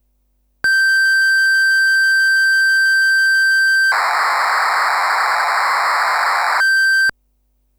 Korg Trident Mk ii 2 cassette tape back-up.
KorgT2tapebackup.wav